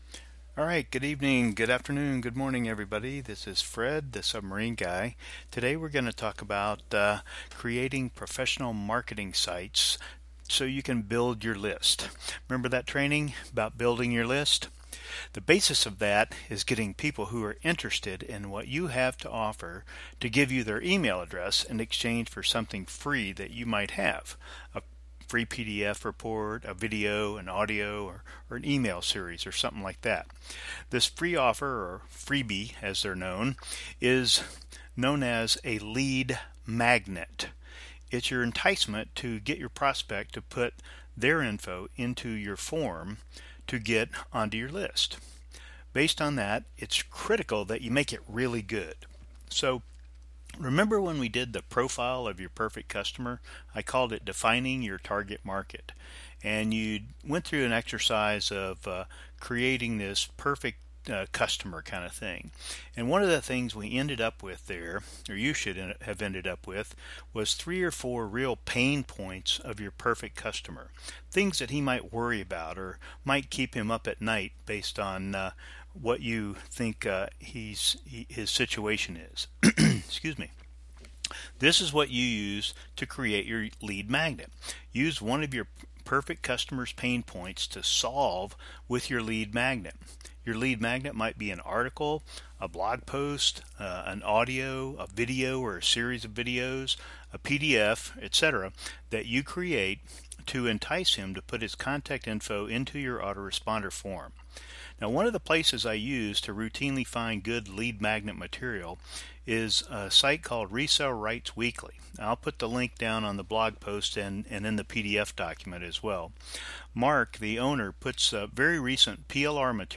Audio Training